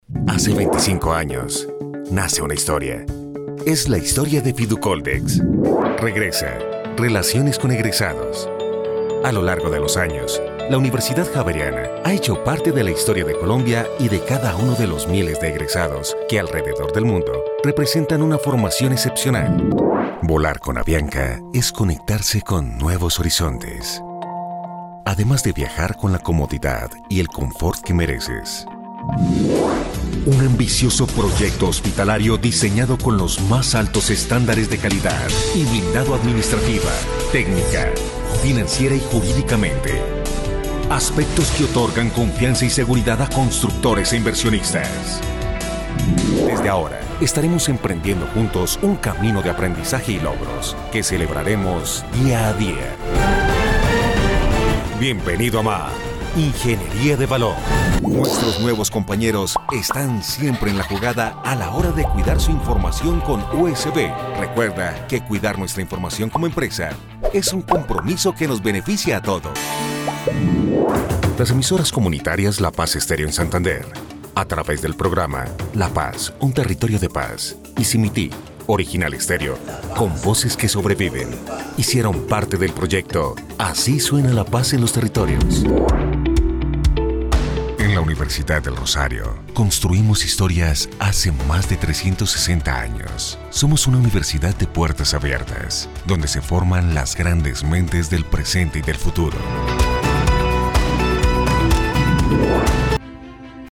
Micrófono Shure Pg Alta Pga27 Condensador Cardioide M-Audio M-Track 2-Channel USB Audio Interface Adobe audition 2020
kolumbianisch
Sprechprobe: Industrie (Muttersprache):